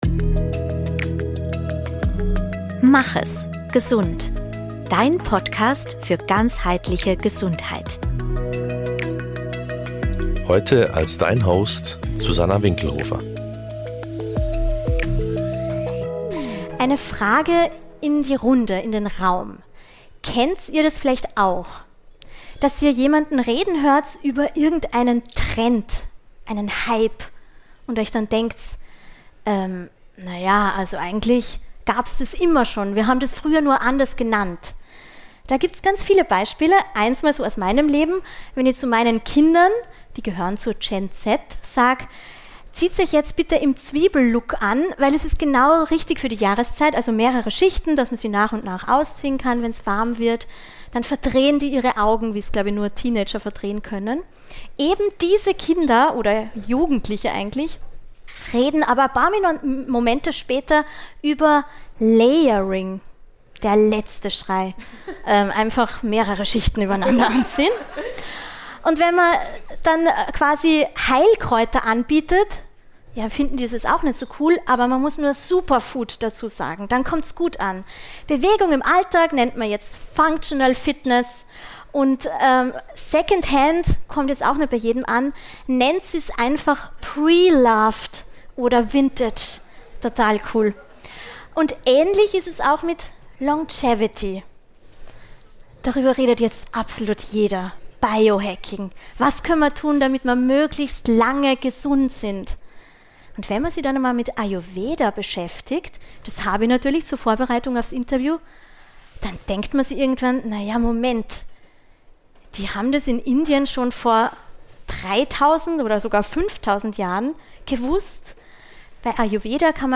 Live vor Publikum im Kulinario in Linz...